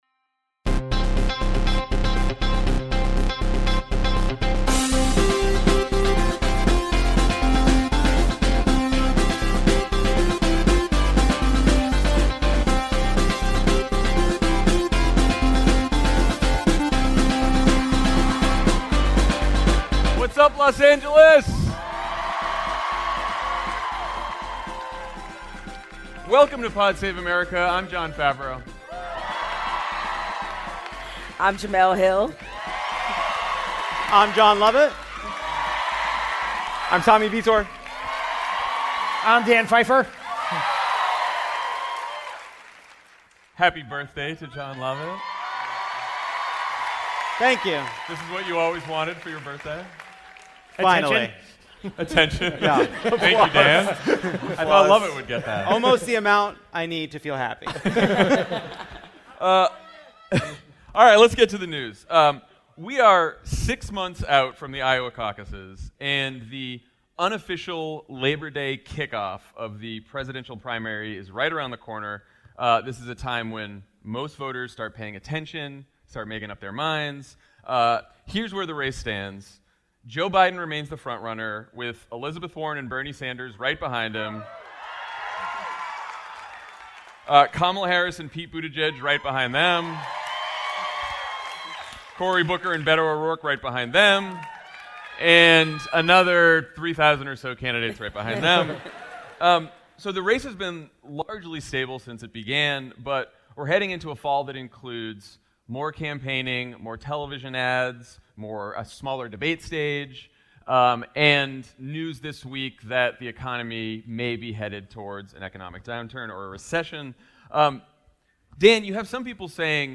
“Vote Save The Greek.” (LIVE from LA!)
The Democratic primary is relatively stable heading into the fall campaign, voters are still trying to define electability, and Stacey Abrams passes on 2020 to focus on protecting the right to vote. Jemele Hill of The Atlantic joins Jon, Jon, Tommy, and Dan on stage at The Greek Theatre in Los Angeles. Amanda Seales joins for a special edition of OK Stop, and Maggie Rogers, Jim James and Best Coast play a round of Pollercoaster Tycoon: Family Feud Edition.